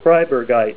Help on Name Pronunciation: Name Pronunciation: Kribergite + Pronunciation